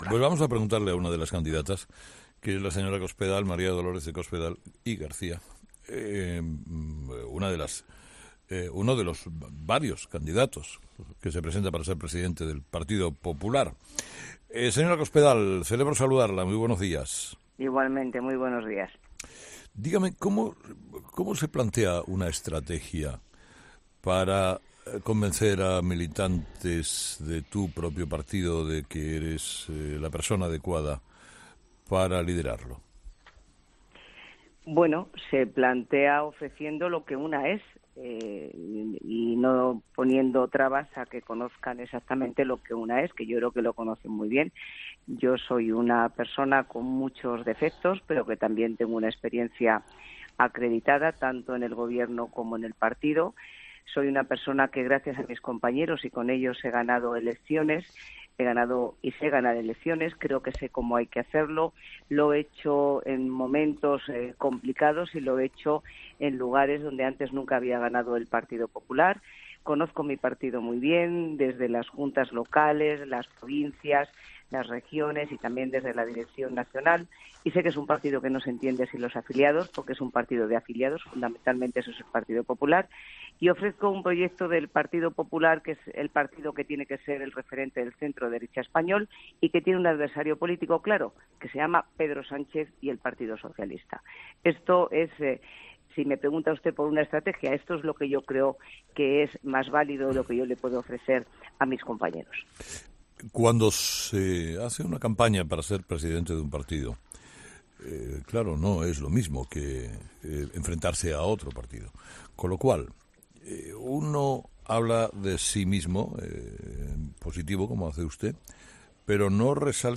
En su entrevista con Carlos Herrera en COPE, la candidata a tomar el relevo de Rajoy ha ensalzado también el valor de la figura femenina en su partido.